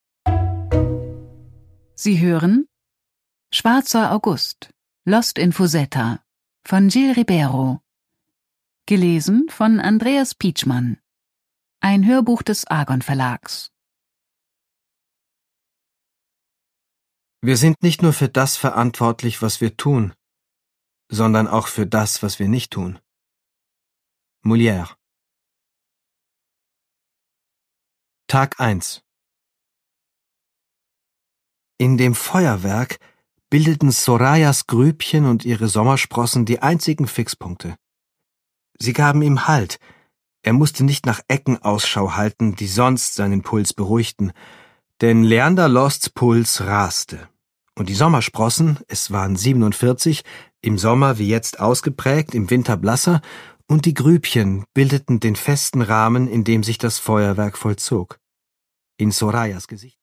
Produkttyp: Hörbuch-Download
Gelesen von: Andreas Pietschmann